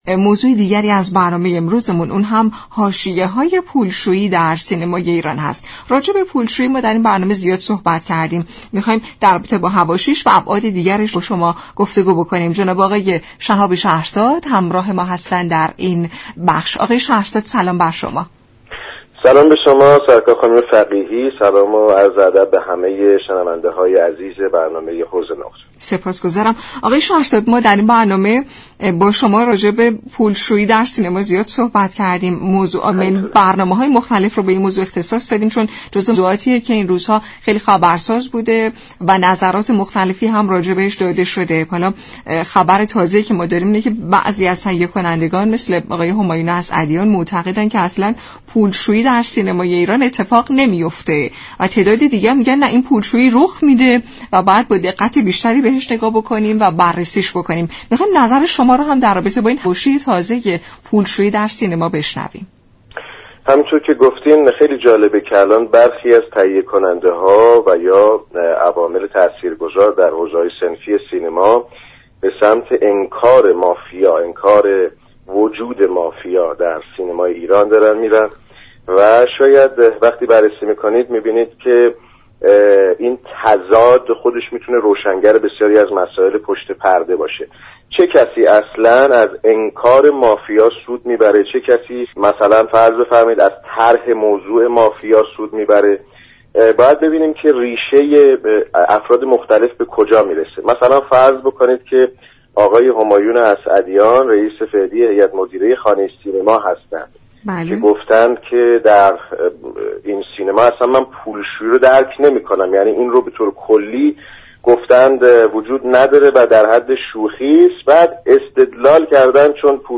گفت و گو با برنامه حوض نقره
برنامه حوض نقره جمعه هر هفته ساعت 17:00 از رادیو ایران پخش می شود.